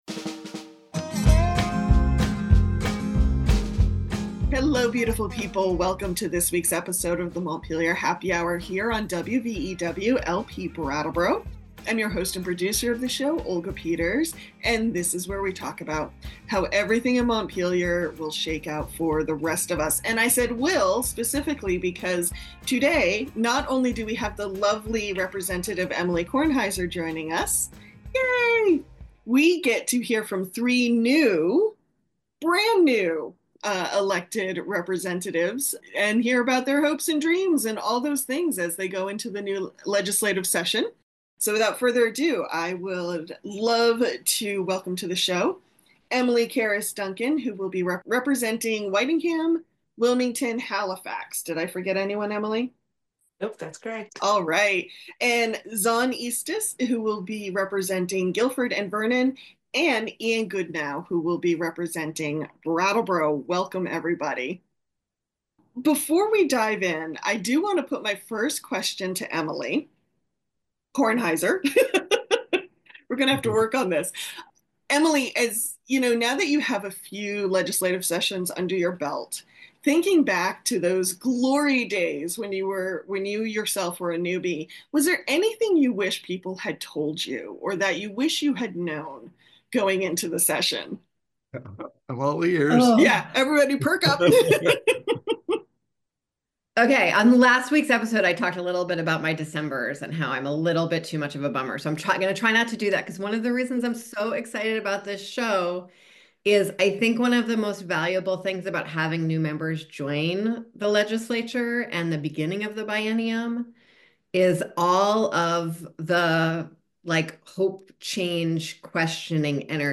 December 20, 2024: Three of Windham County's newly elected representatives join today's show. Emily Carris-Duncan, Zon Eastes, and Ian Goodnow discuss their expectations for January. Veteran Rep. Emilie Kornheiser shares her experience and thoughts for the upcoming biennium.